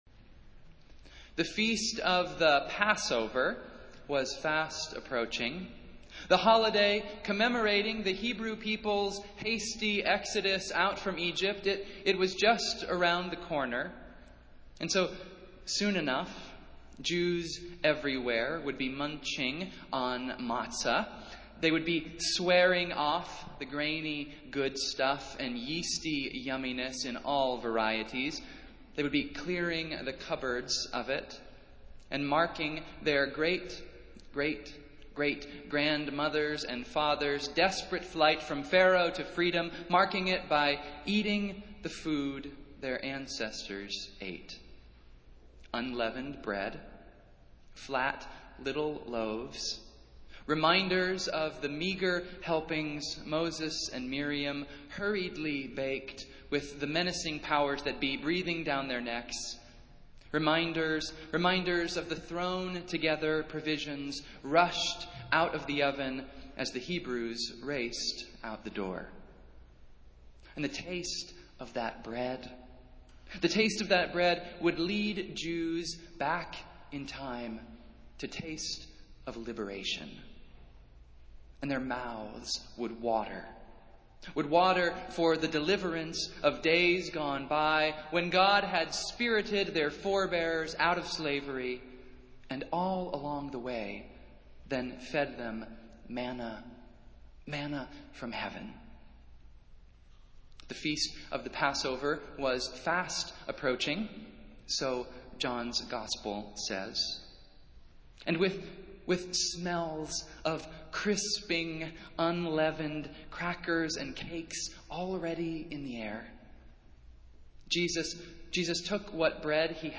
Festival Worship - Fourth Sunday of Easter